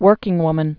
(wûrkĭng-wmən)